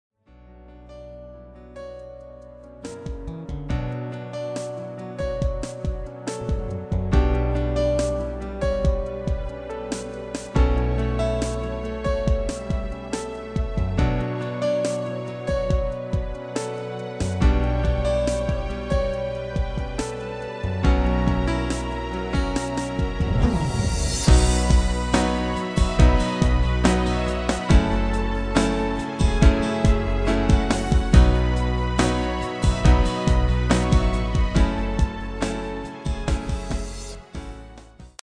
Midifile
Demo/Koop midifile
U koopt een GM-Only midi-arrangement inclusief:
- Géén vocal harmony tracks
Demo's zijn eigen opnames van onze digitale arrangementen.